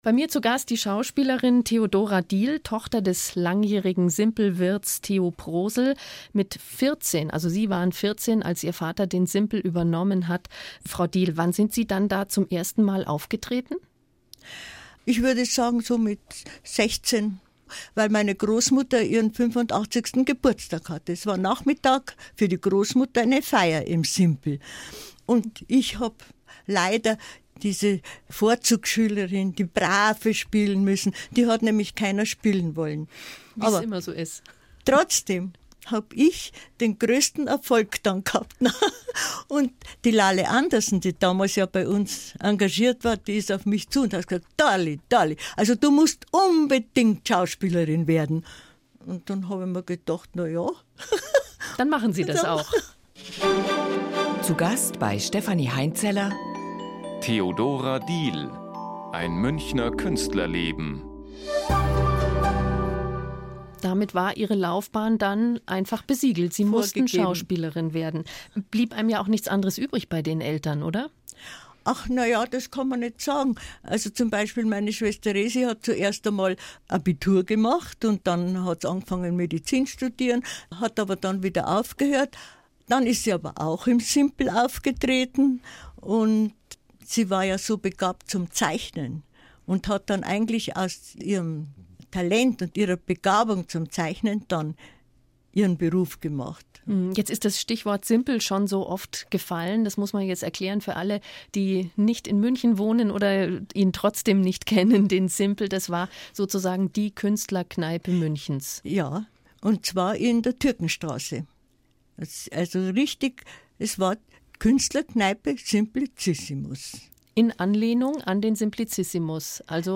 BR-Interview